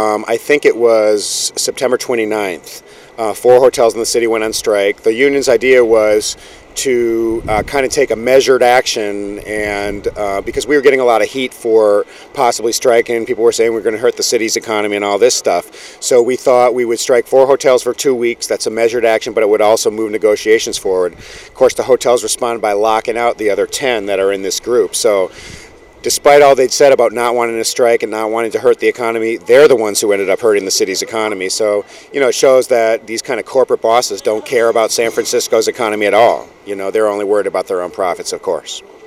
More Audio from Hotel pickets on 10/10
A call was put out on indybay a few days in advance and as a result many people showed up sunday night (10/10) to express their support for the striking and locked out hotel workers. These are some of the sounds from the night's activities.